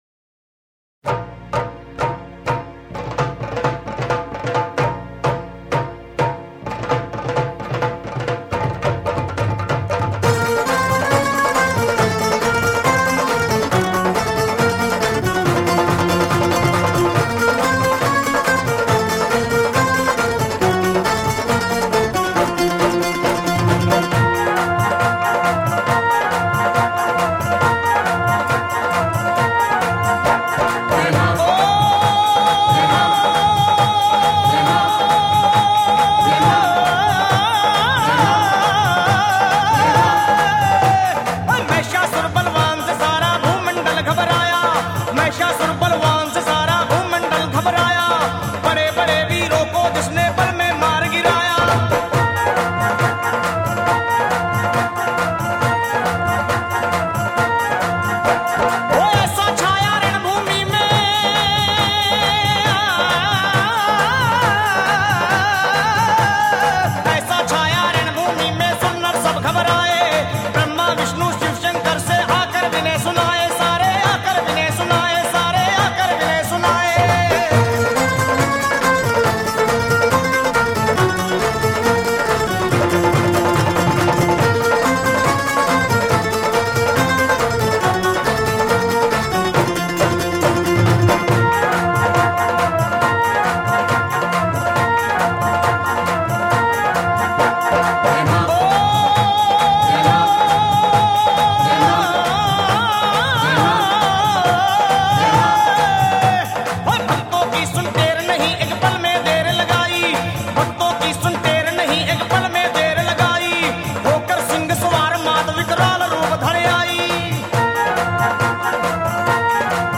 Mp3 Mata bhajan download
Navratri Bhajans